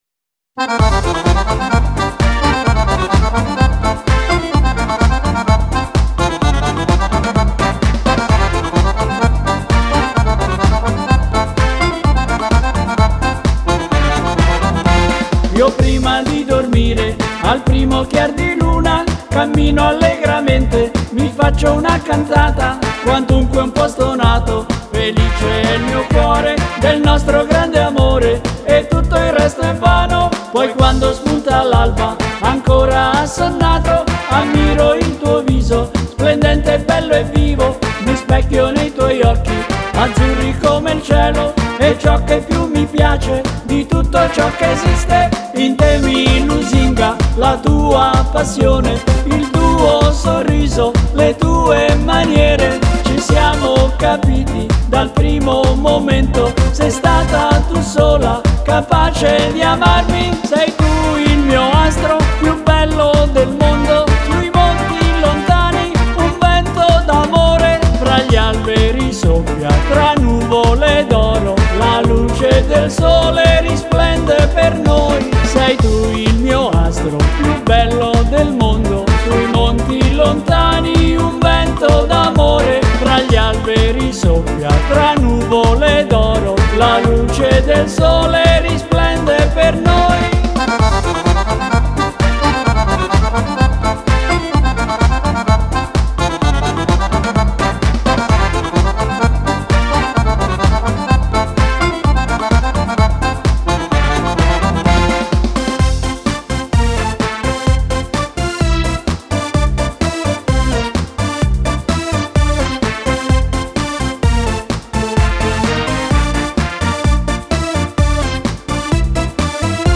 Ballo di gruppo
Dieci canzoni ballabili
Fisarmonica